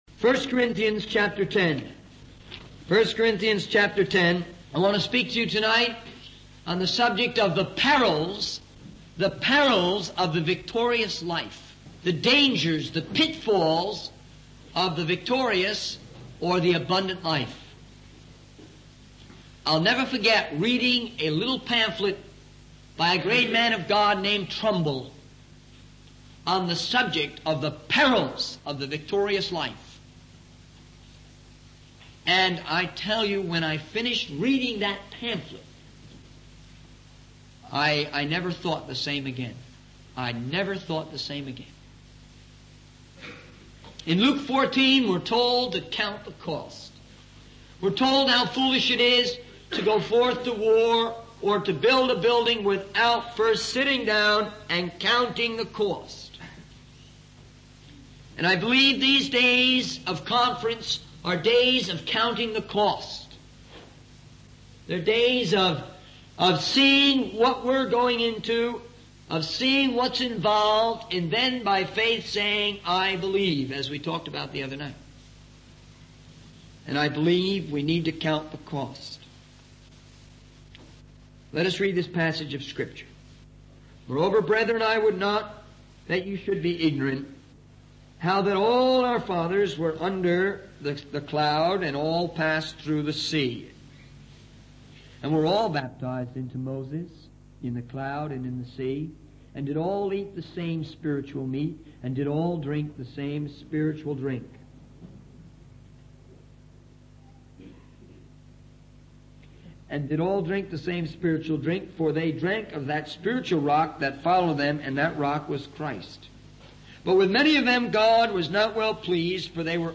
In this sermon, the speaker addresses the potential dangers and pitfalls that young people may face in their spiritual journey. He emphasizes the importance of being realistic and looking at historical examples of youth movements and missionary movements that have ended up on a negative path. The speaker urges young people to take time to reflect, study the word of God, and get involved in their local church.